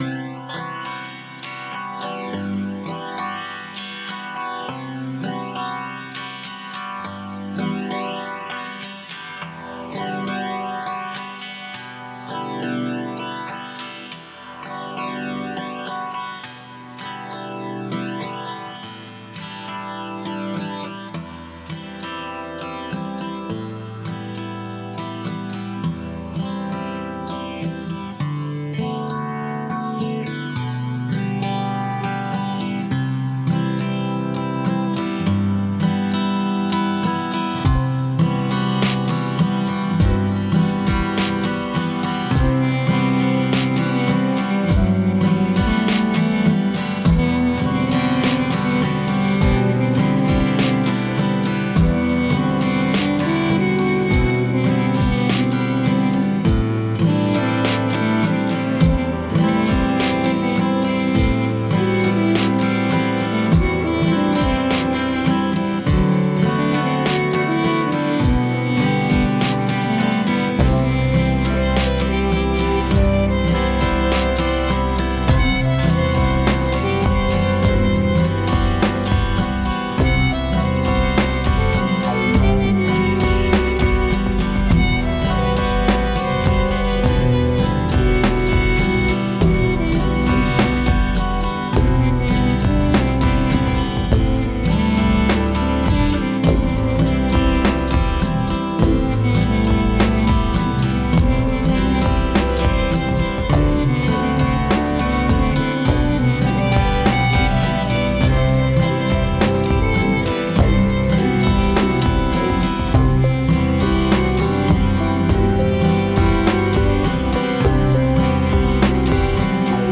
I wanted to create a song based on an acoustic guitar pattern I had been playing with, with fairly slow-moving harmony. The vibe of the loop was “chill,” so I wanted to keep that vibe going with the drumbeat and instrumentation.
It’s instrumental, because I’ve been fighting either a cold or a secondary infection from a cold for about two weeks now, so it’s tougher than usual to incorporate singing into these compositions.
The acoustic guitar is a live take in my studio, using an X/Y condenser microphone pair and a Shure SM-7B to capture some of the rounder frequencies, and I built the rest of the song around that.